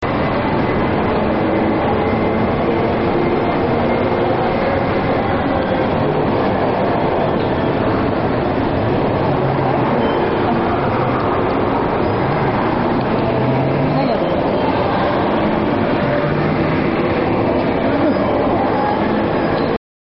でも、隣がゲームセンターなので騒音が鳴り響き、
カフェの近くで録音した音声（
• 基本的にいろんな場所がうるさかったです